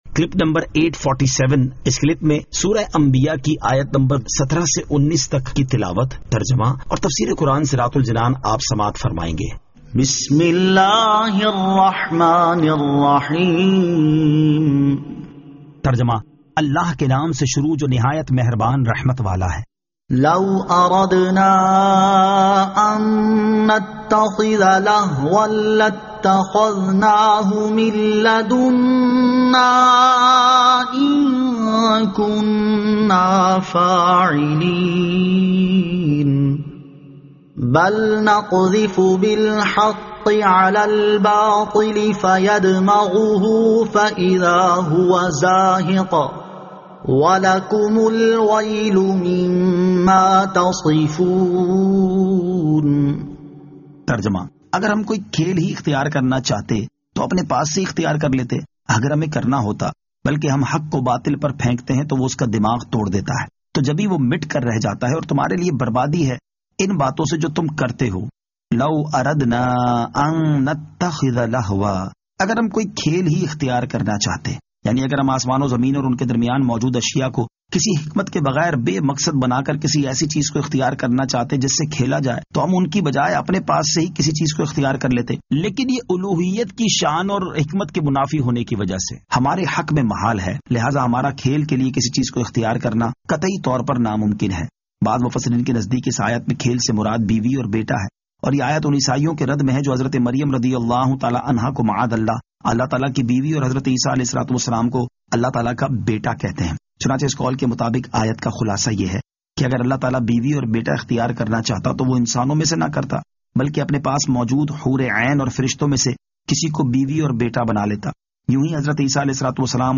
Surah Al-Anbiya 17 To 19 Tilawat , Tarjama , Tafseer
2022 MP3 MP4 MP4 Share سُورَۃُ الأَ نبِیَاٰءِ آیت 17 تا 19 تلاوت ، ترجمہ ، تفسیر ۔